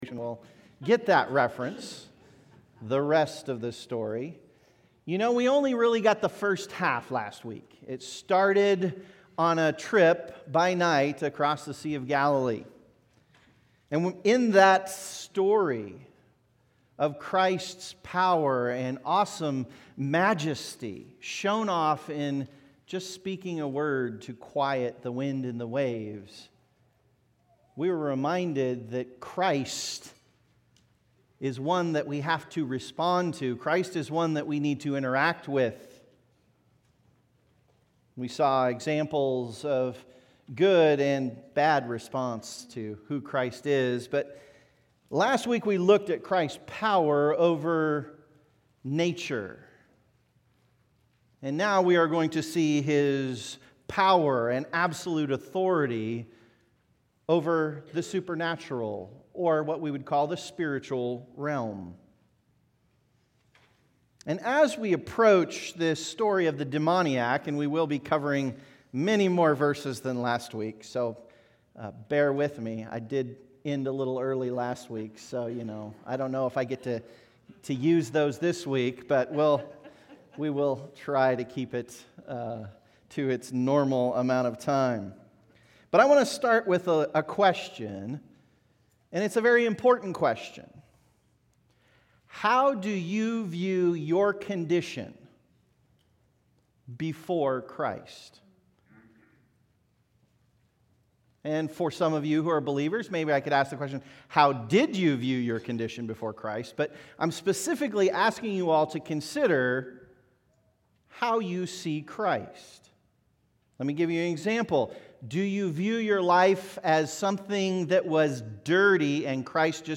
The Savior of the World Current Sermon